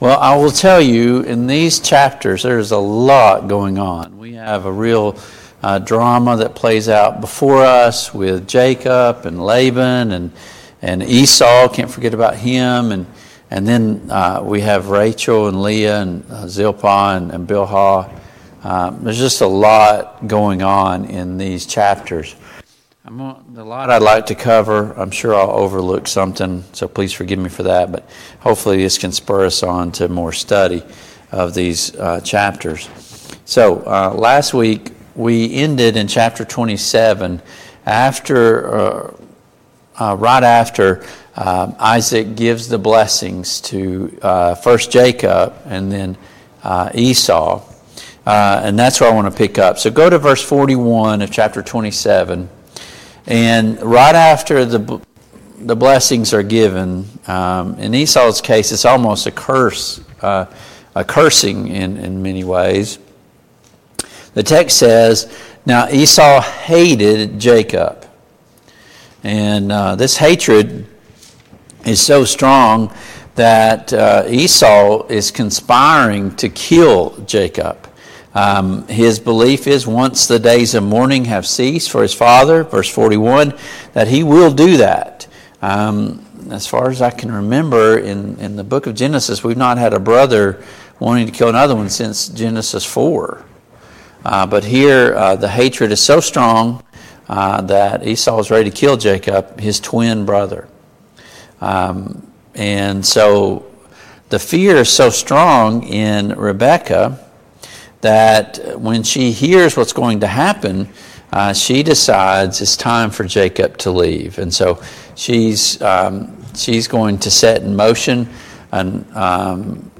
Service Type: Family Bible Hour Topics: Jacob and Rachel